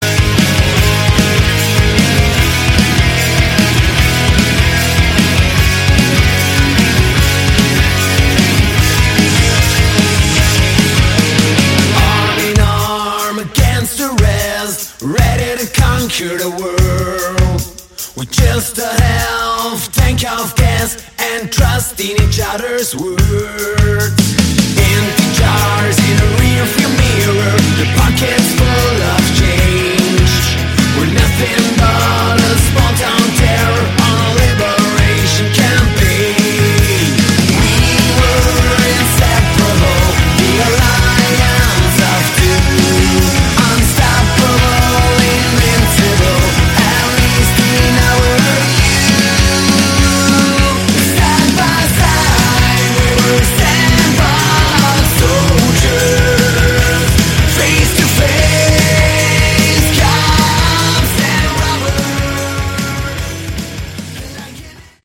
Category: Hard Rock
vocals, guitar
lead guitar